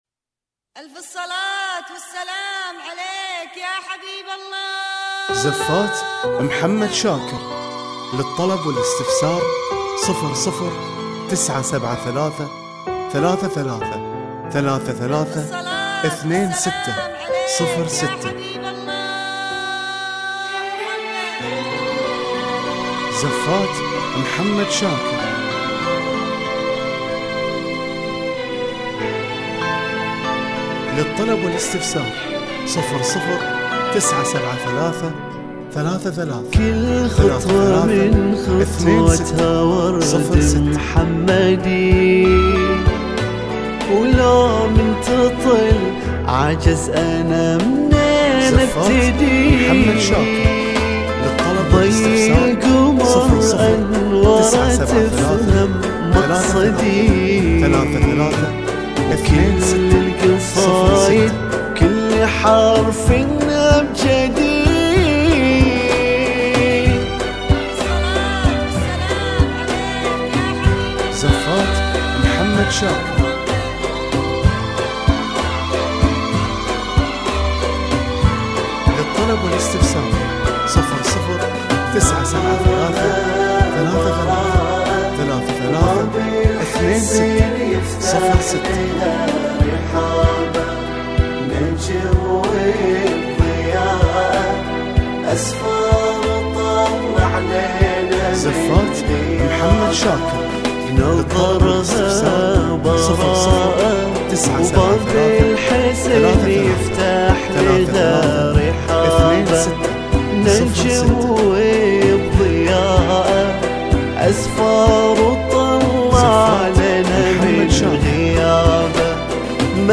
بالموسيقى